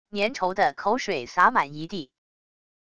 粘稠的口水洒满一地wav音频